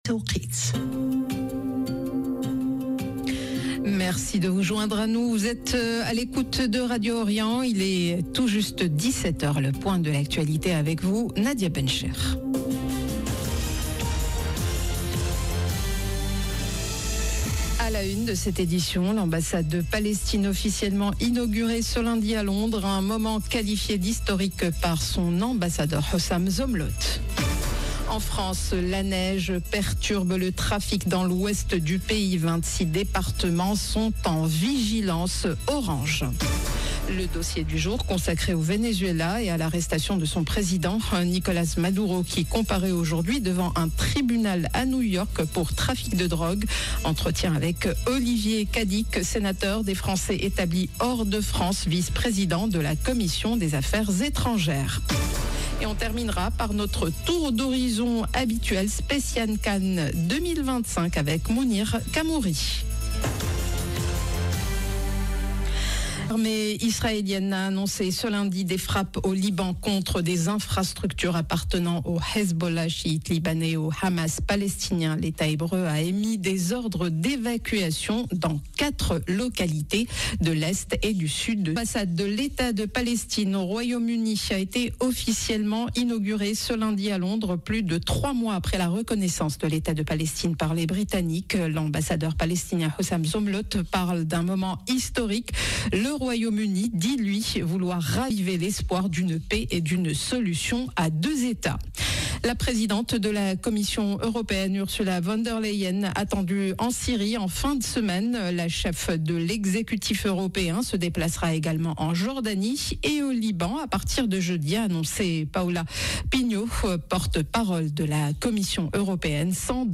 JOURNAL DE 17H
Entretien avec Olivier Cadic, Sénateur des Français établis hors de France, vice-président de la Commission des Affaires étrangères.